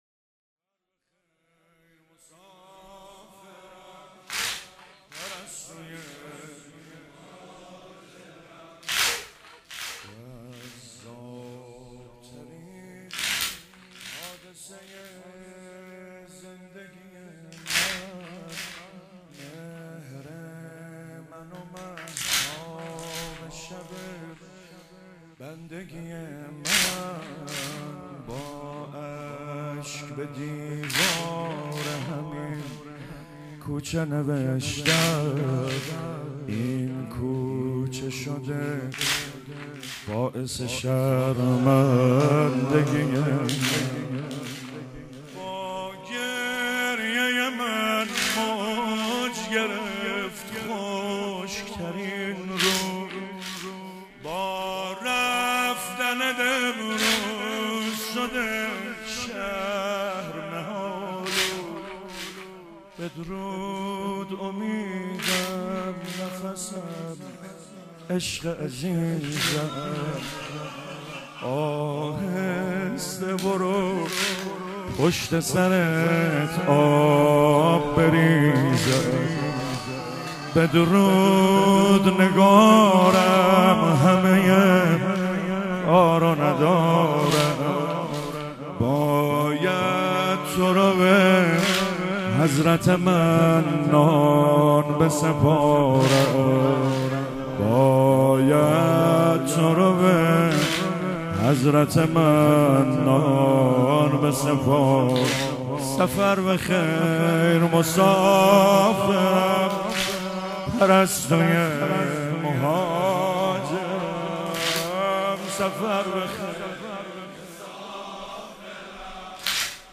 فاطمیه 97 - 28 دی - تهران - واحد - ای مسافرم پرستوی مهاجرم
فاطمیه 97